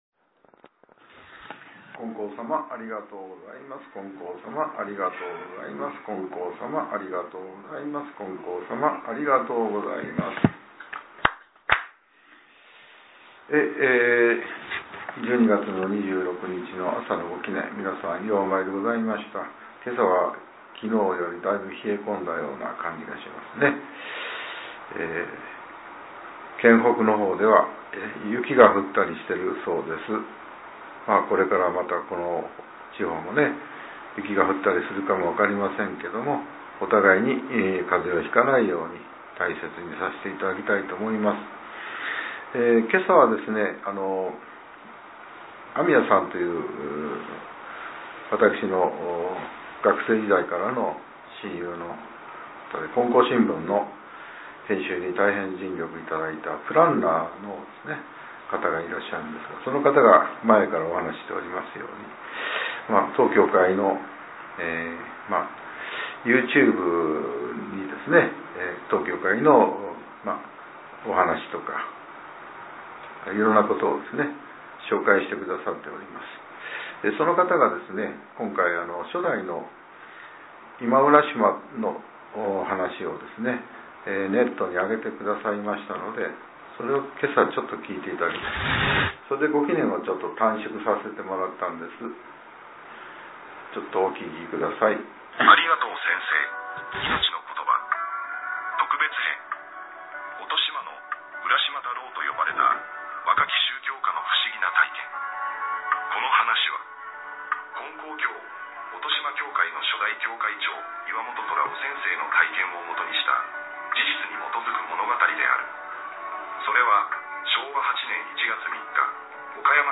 令和７年１２月２５日（朝）のお話が、音声ブログとして更新させれています。